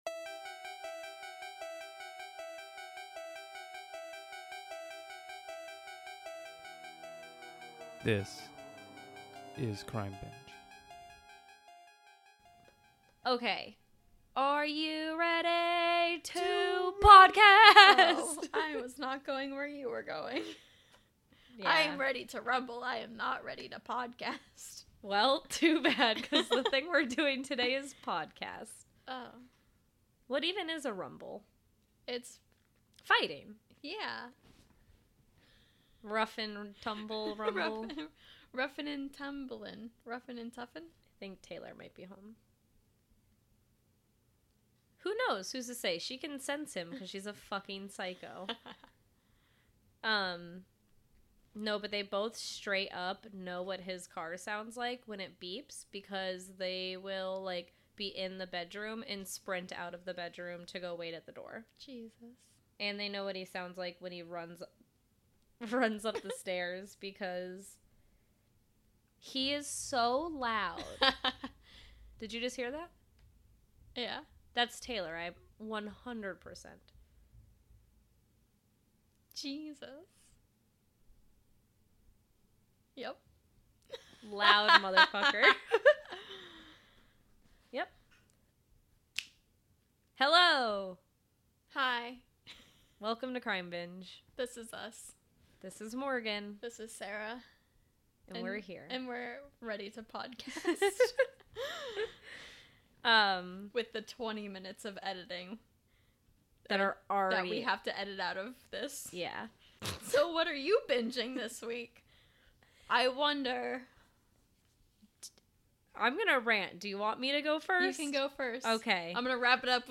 This week on Crime Binge the girls discuss serial killer Joachim Kroll, also known as the Duisburg Man-Eater.